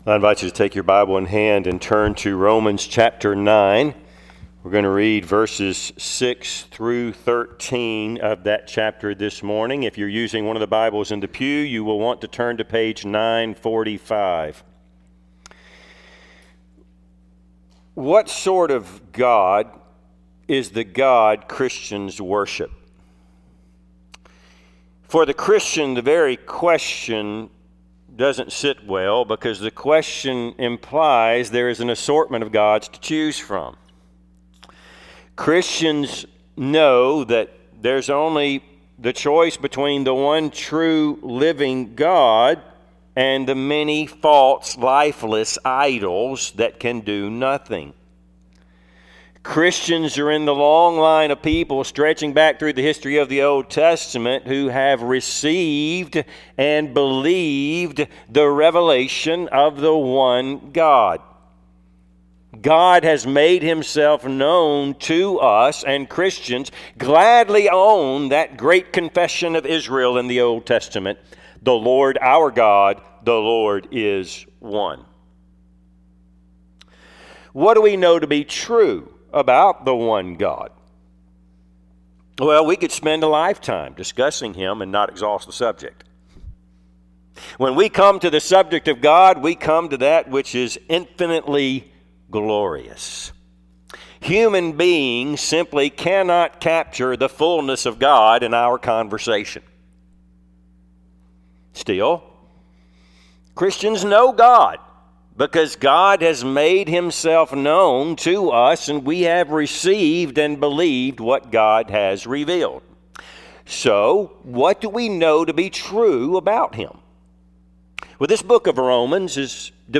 Service Type: Sunday AM Topics: Election , God's Sovereignty , Salvation